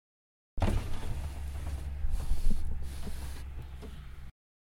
Звук опускания окна в фургоне с электроприводом